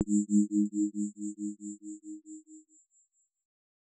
tone3.R.wav